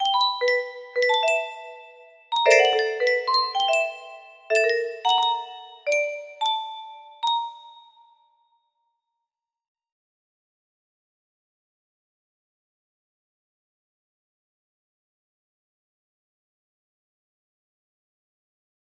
lalalalalalalalala best days music box melody